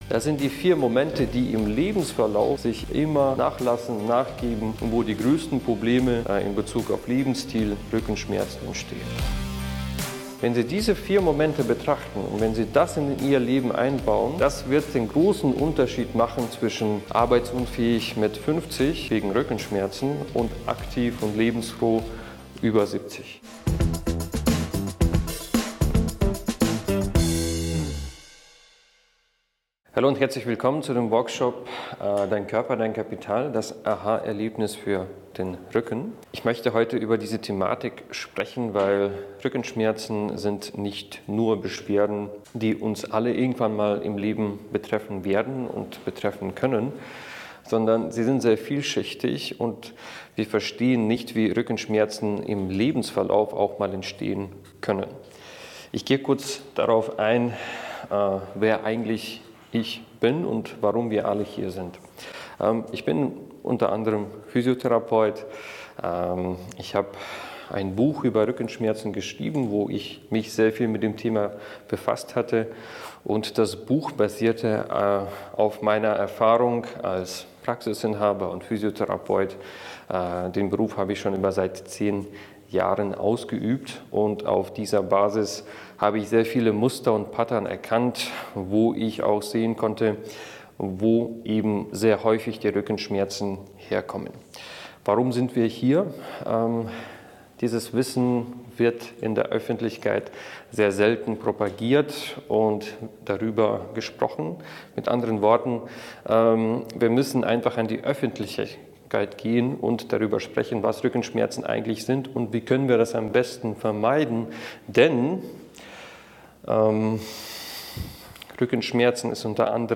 In diesem Workshop wird die Thematik der Rückenschmerzen umfassend behandelt.